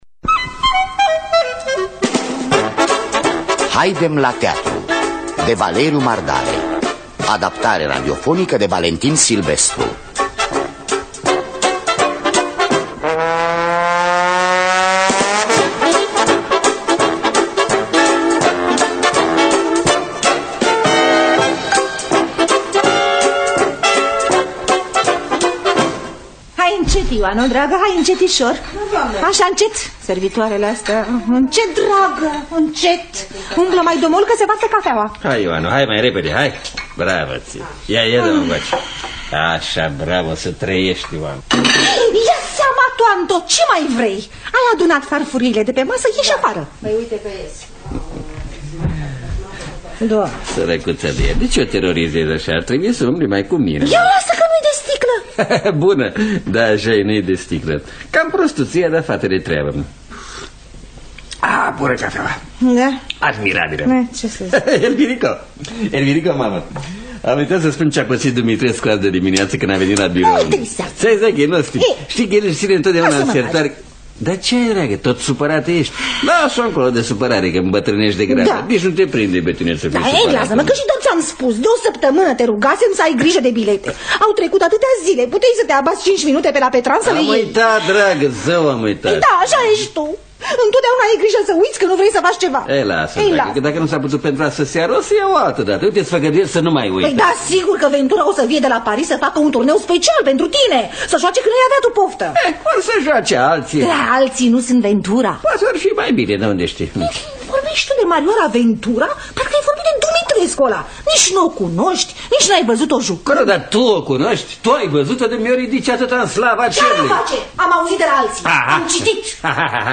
“Haidem la teatru!” de Valeriu Mardare – Teatru Radiofonic Online
În distribuţie: Radu Beligan, Valeria Gagealov, Octavian Cotescu, Ileana Stana Ionescu, Rodica Popescu-Bitănescu, Mitică Popescu.